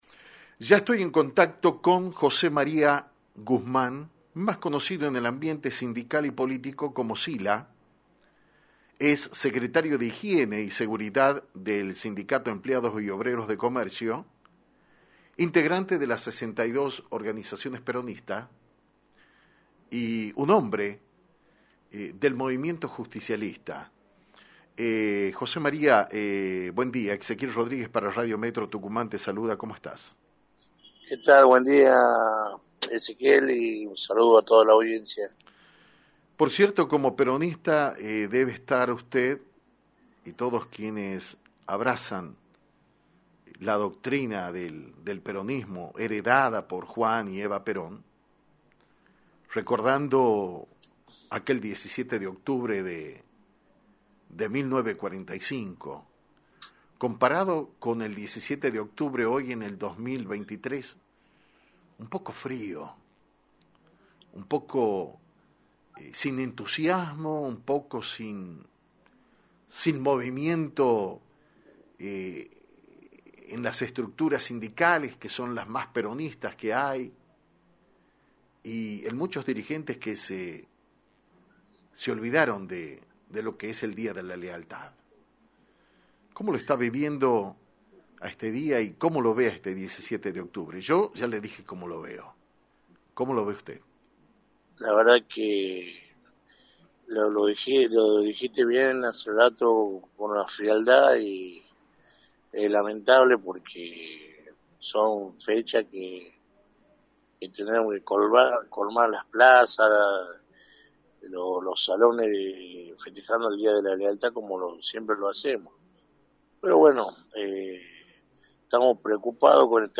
En dialogo con Actualidad en Metro